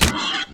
boar_hit2.ogg